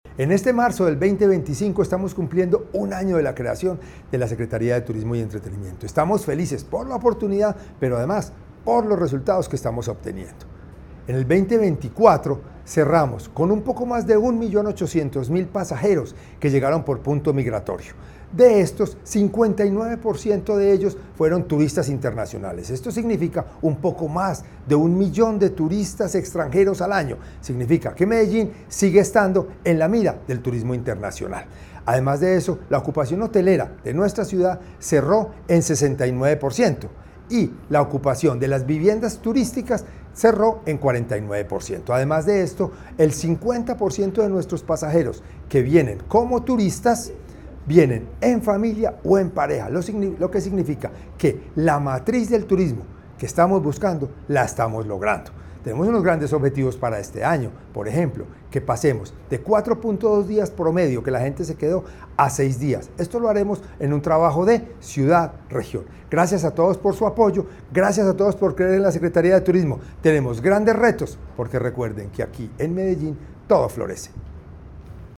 Declaraciones secretario de Turismo y Entretenimiento, José Alejandro González.
Declaraciones-secretario-de-Turismo-y-Entretenimiento-Jose-Alejandro-Gonzalez..mp3